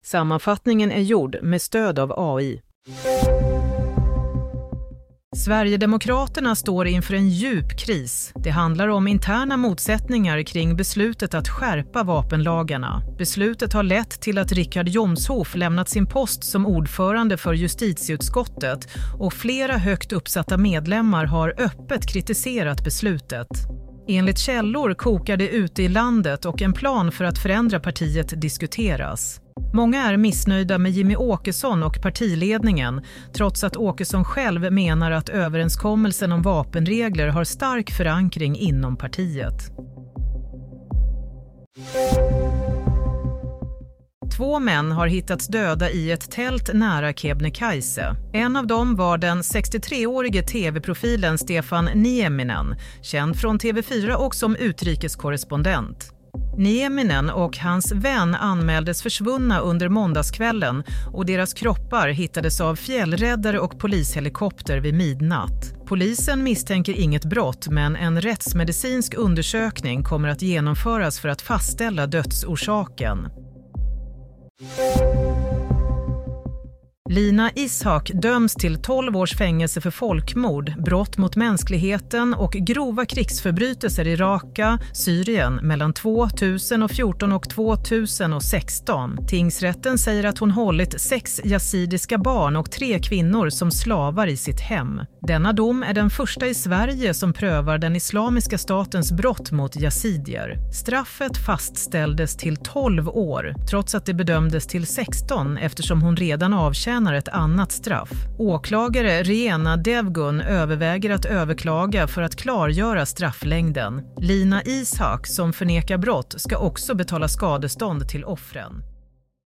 Nyhetssammanfattning - 11 februari 16.00
Sammanfattningen av följande nyheter är gjord med stöd av AI.